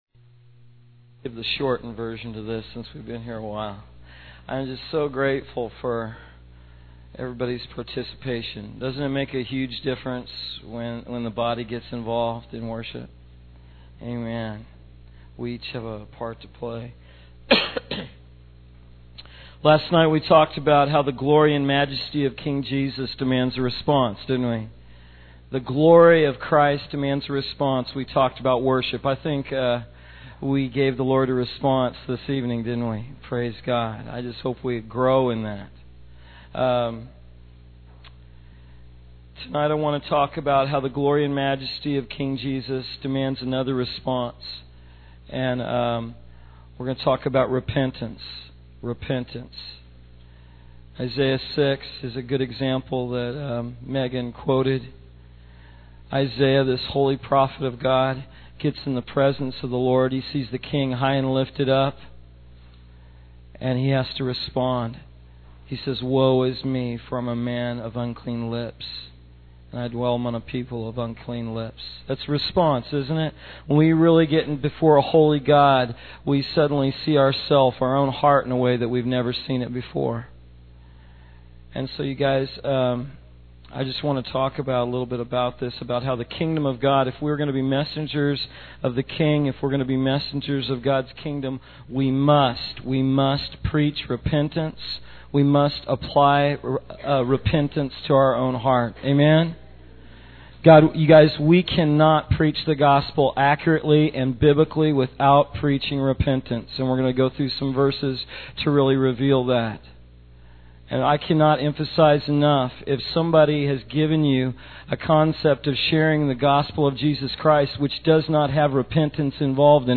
In this sermon, the speaker emphasizes the importance of responding to the glory and majesty of King Jesus. He highlights the significance of worship as a response and encourages the congregation to grow in their worship. The speaker then shifts the focus to repentance, using the example of Isaiah 6 and John the Baptist to illustrate the necessity of repentance in the presence of God.